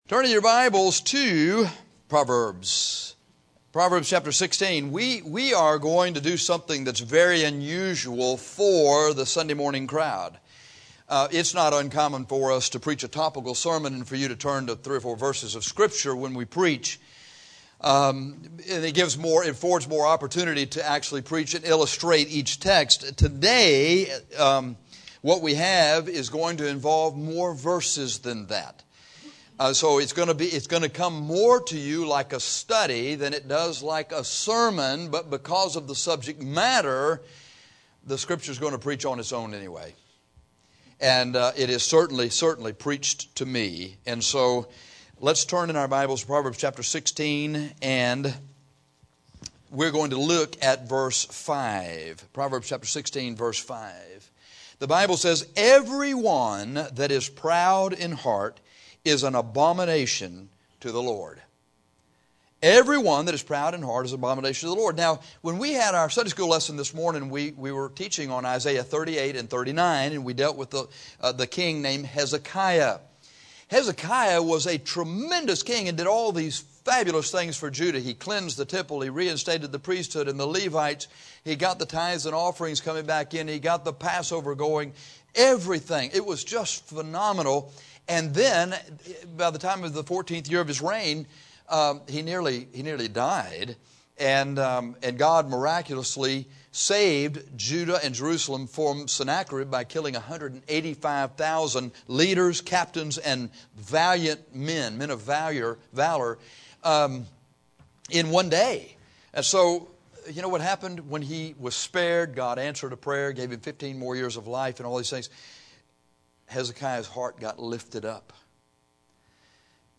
In this sermon we will read many verses so that we can get God’s view on pride.